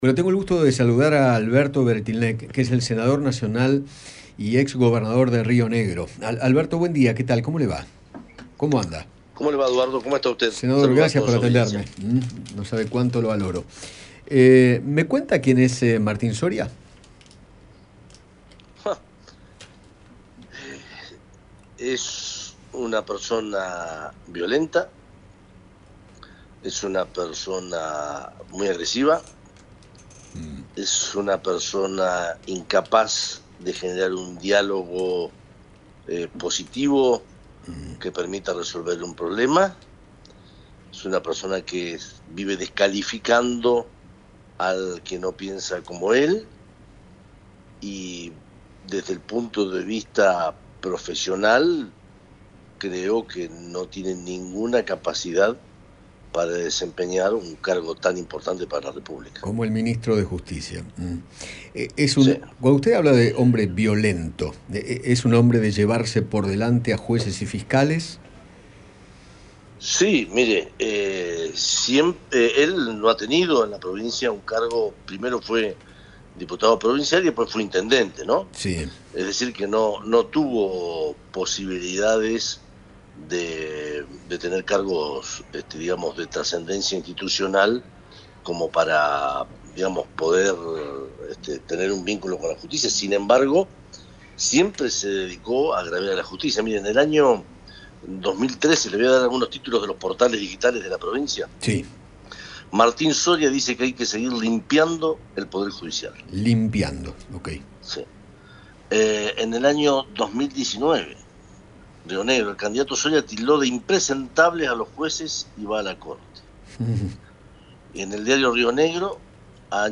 Alberto Weretilneck, senador nacional, dialogó con Eduardo Feinmann sobre la designación de Martin Soria como nuevo ministro de justicia.